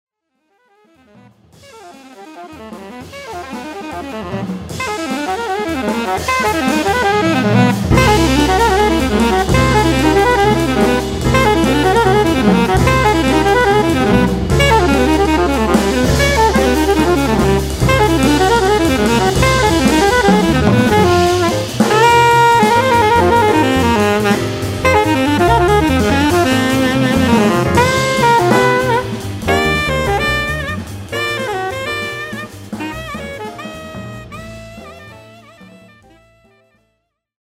alto and sopranino saxophones
piano
bass
drums